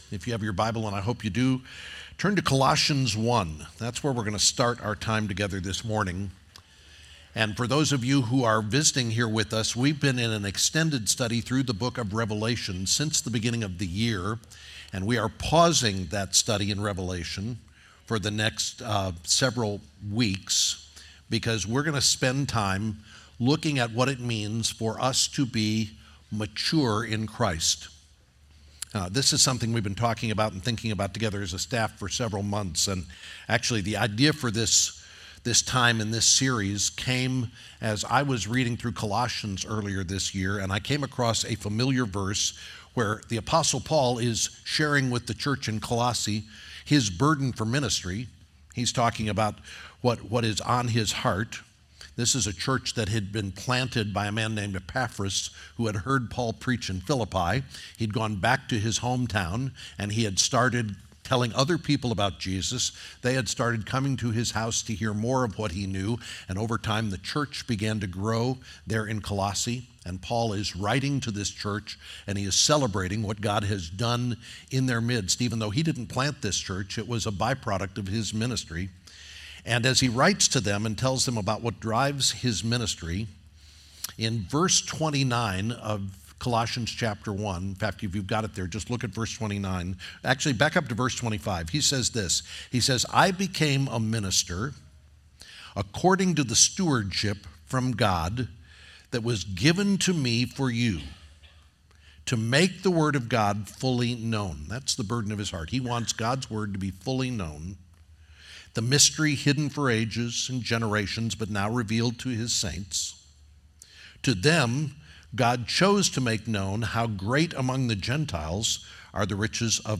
2024 Mature Disciples Colossians 1:25-29 The first sermon in a four week series at the end of the summer in 2024 examining what the true marks of a mature disciple are.